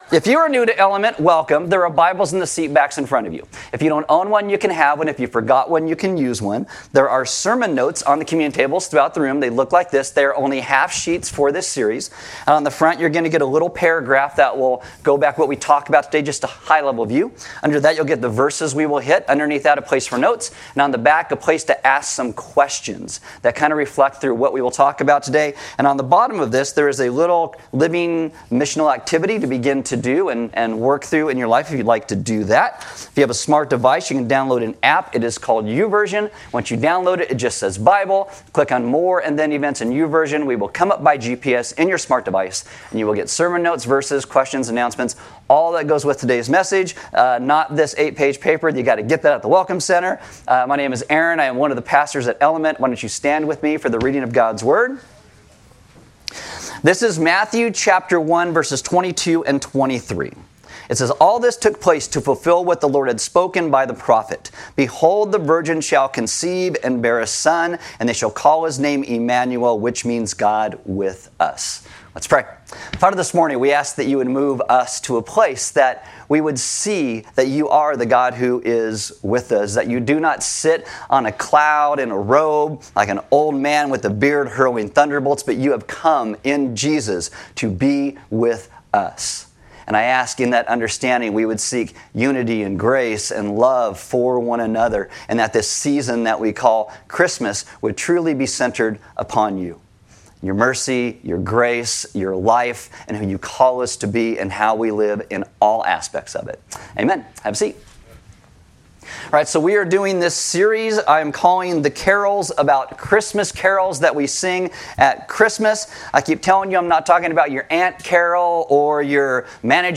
Service Audio Message Today we will look at the theological depth of the Christmas carol “O Come O Come Emmanuel” through the lens of Matthew 1:22-23.